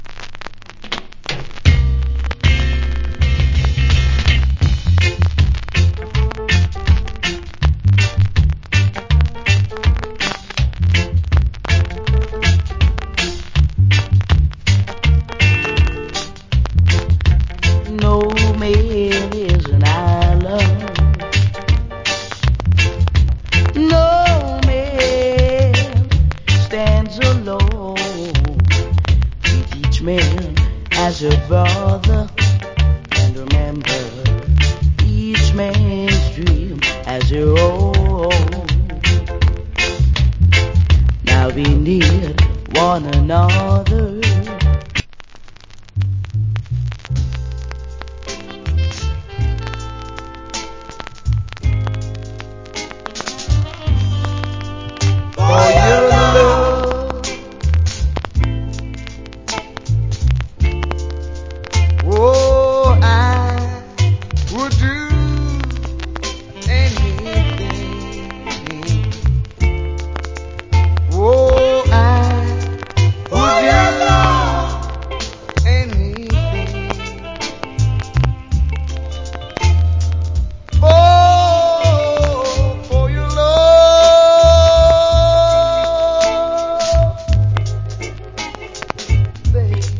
Reggae Vocal.